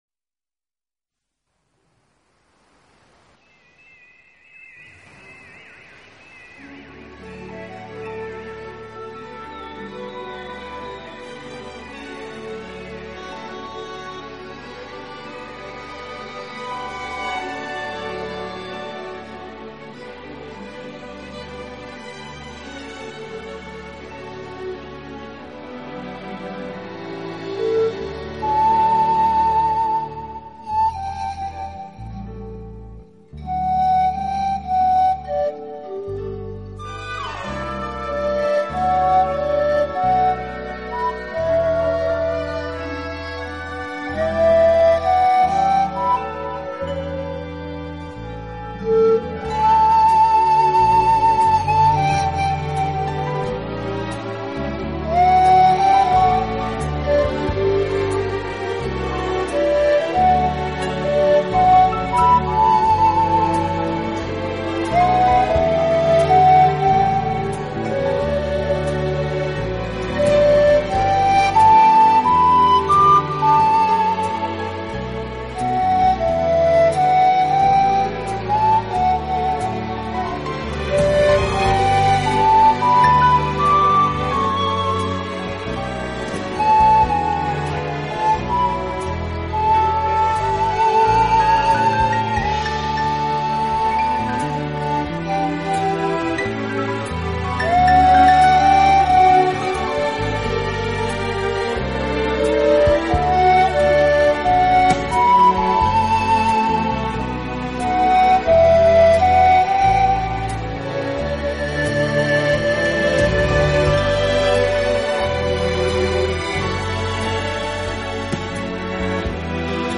【精品排箫】
超广角音场的空间感演绎，大自然一尘不染的精华，仿佛让你远离凡尘嚣暄，